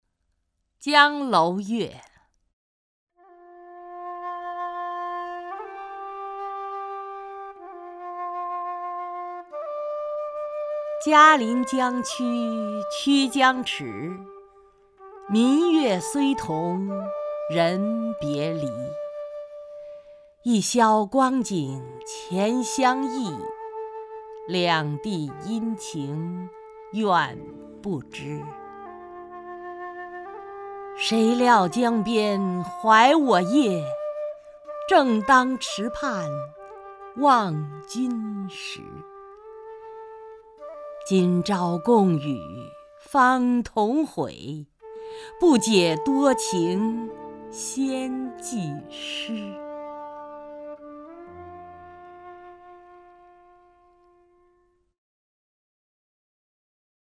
首页 视听 名家朗诵欣赏 曹雷
曹雷朗诵：《江楼月》(（唐）白居易)